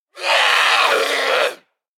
chase_7.ogg